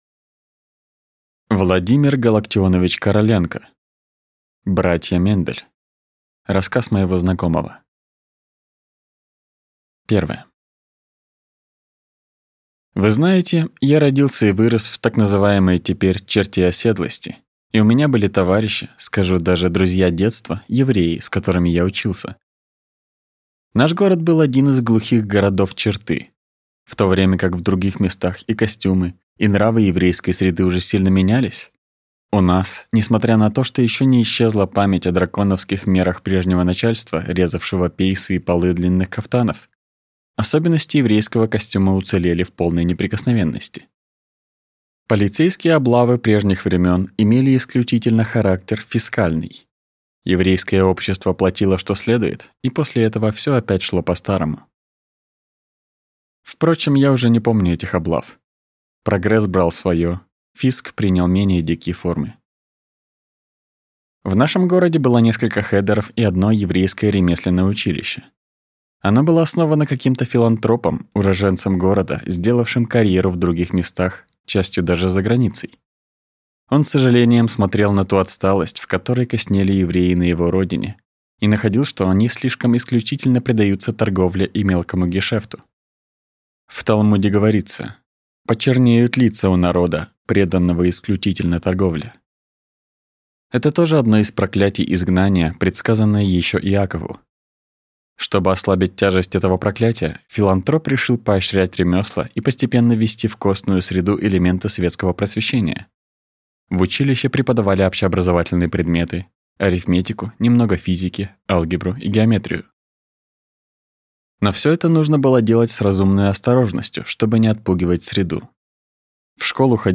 Аудиокнига Братья Мендель | Библиотека аудиокниг